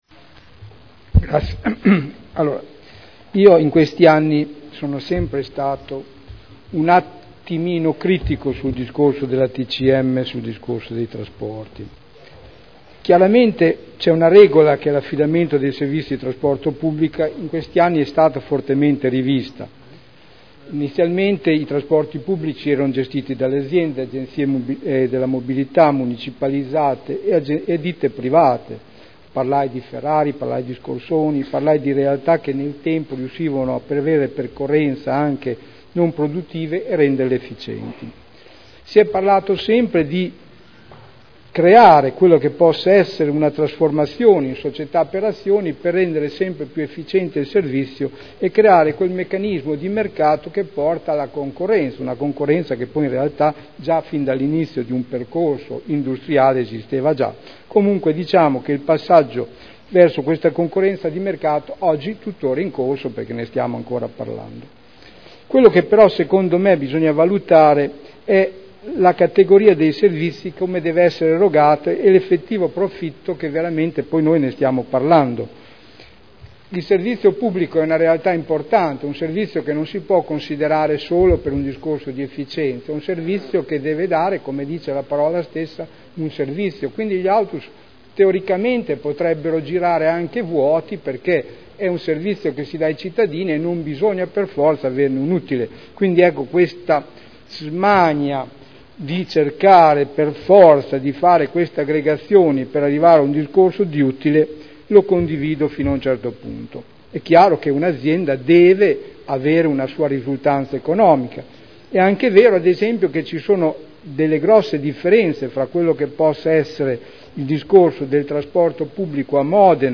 Seduta del 3 ottobre 2011 Proposta di deliberazione: Aggregazione dei soggetti affidatari del Servizio di Trasporto Pubblico Locale nei bacini di Modena, Reggio Emilia e Piacenza Dibattito su proposta di delibera e odg presentati in corso del Consiglio Comunale